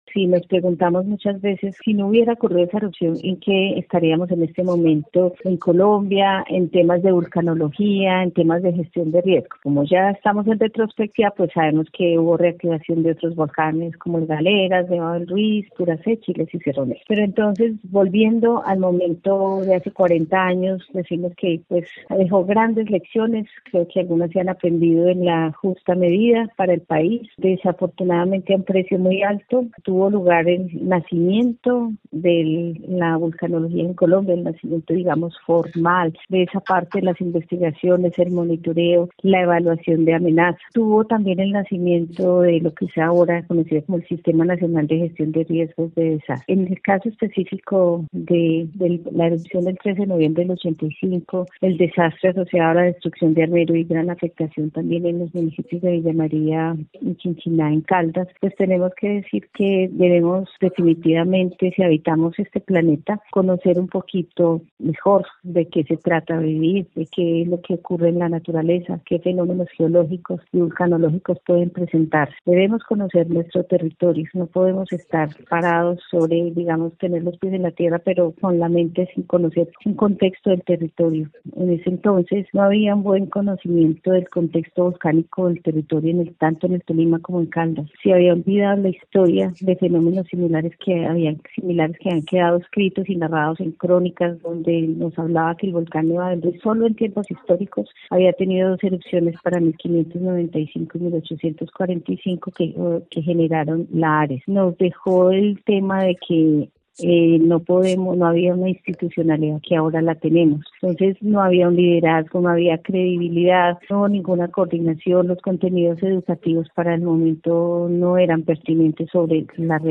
recordó en diálogo con Caracol Radio que la emergencia del 13 de noviembre de 1985